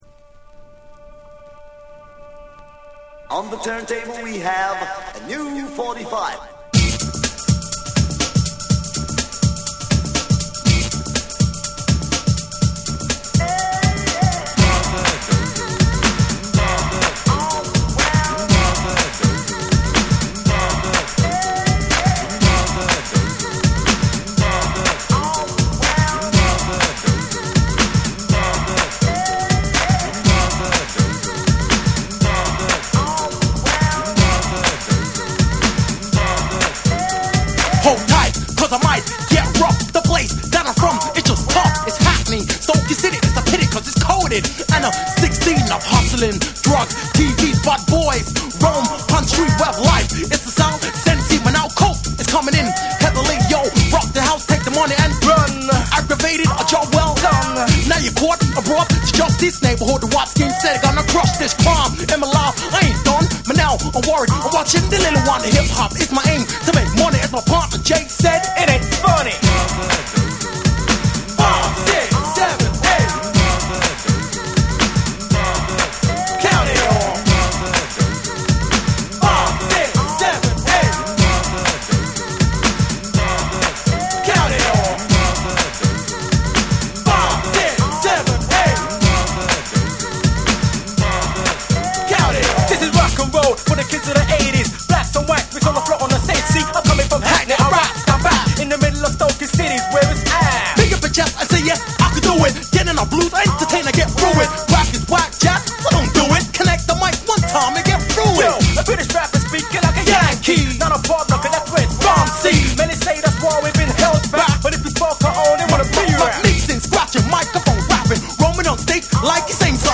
レイブにも通ずる要素を含むヒップハウスのトラックに、煽りを効かせた男性MCがフロウする
BREAKBEATS / TECHNO & HOUSE